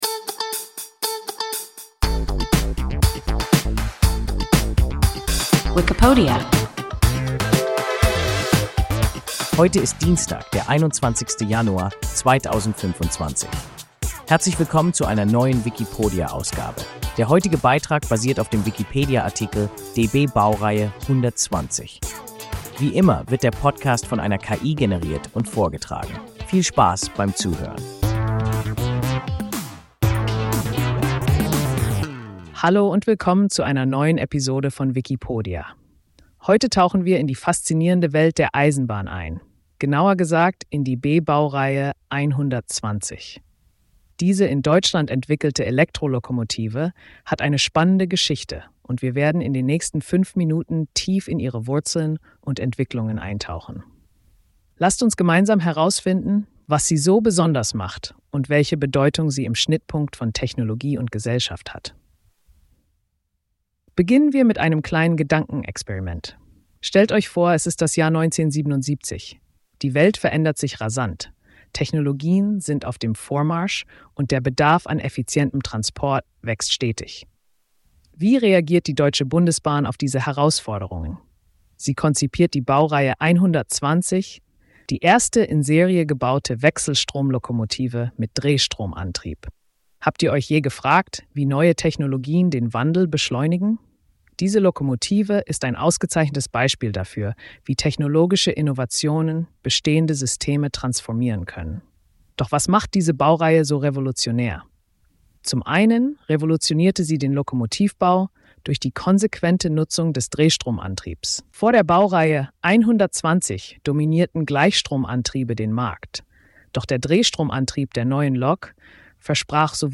DB-Baureihe 120 – WIKIPODIA – ein KI Podcast